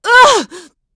Isolet-Vox_Damage_03.wav